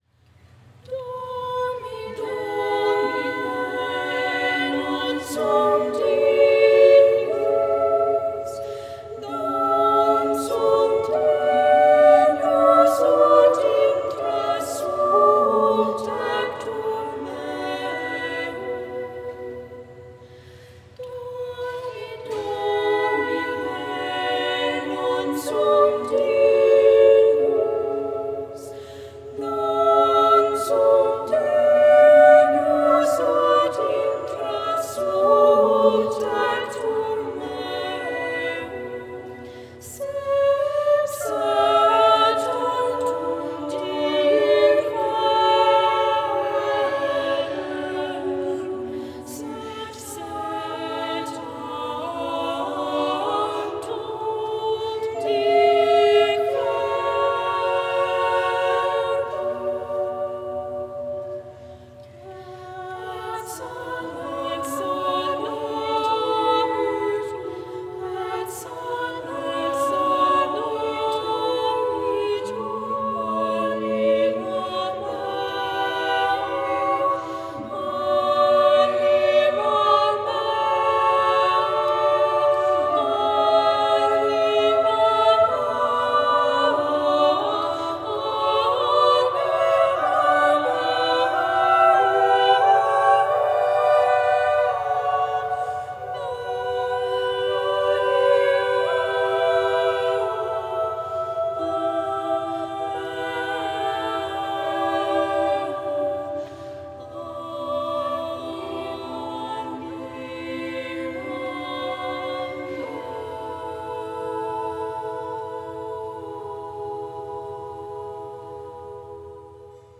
However, attending the 5:00pm Mass were a few young ladies who sing very well.
Someone made a recording of us singing, and it actually sounds pretty good. I consider that piece—“Dómine, Non Sum Dignus”—to be a masterpiece.